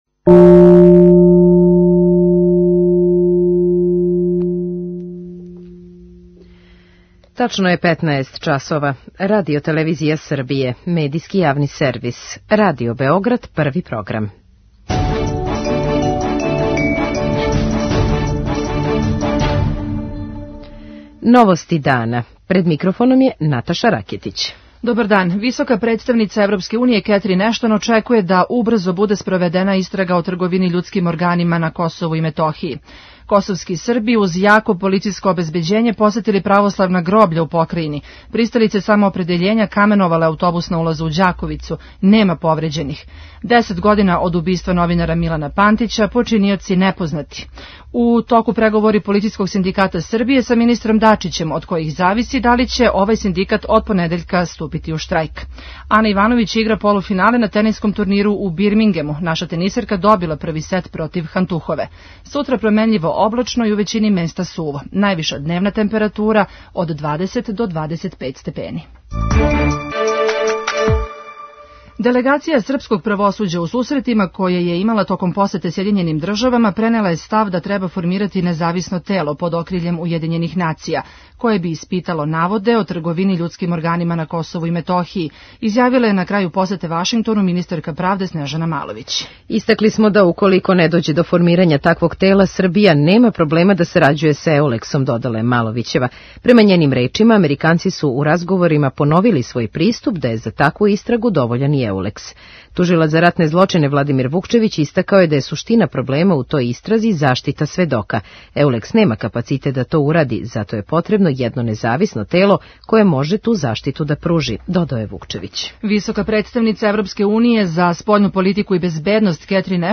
О томе, као и о другим горућим проблемима у здравству у Новостима дана говори министар здравља Зоран Станковић.
преузми : 14.85 MB Новости дана Autor: Радио Београд 1 “Новости дана”, централна информативна емисија Првог програма Радио Београда емитује се од јесени 1958. године.